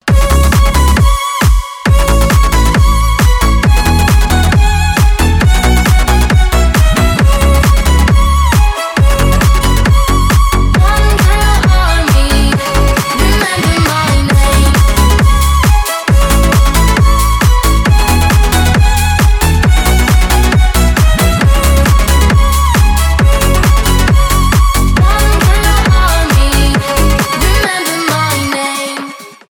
скрипка , клубные